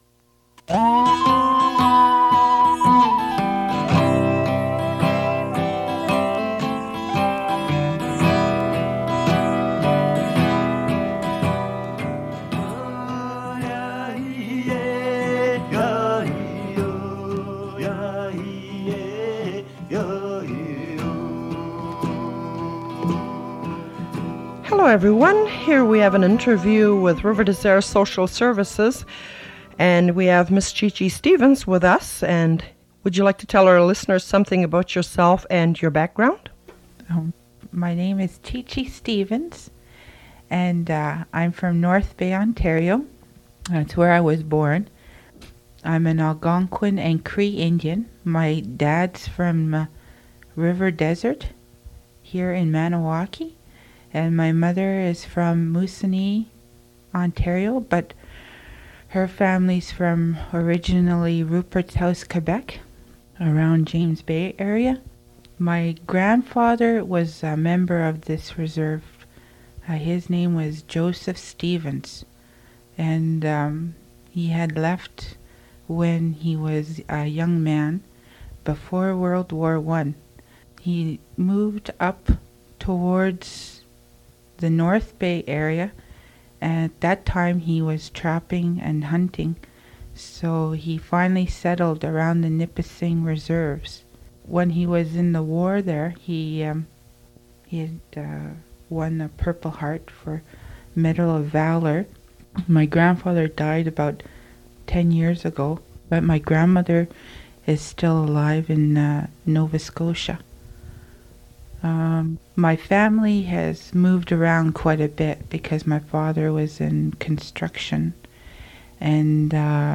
Q&A session